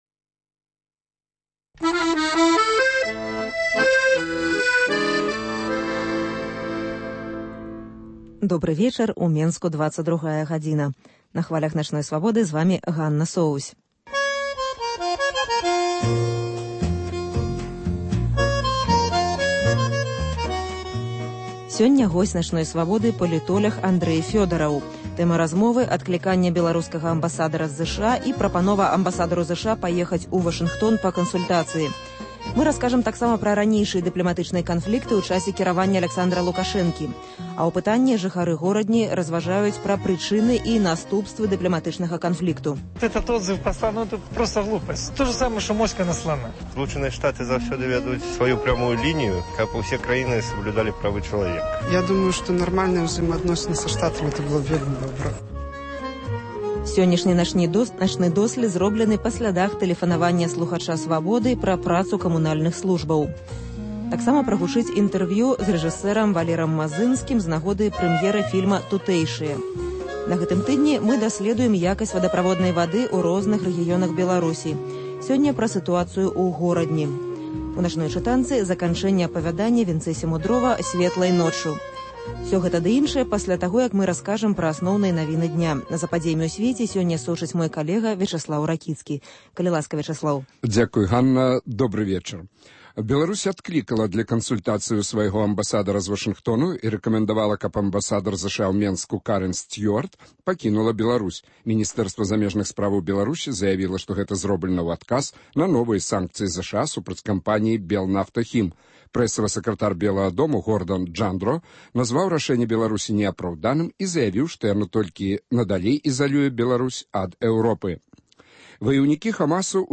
Інтэрвью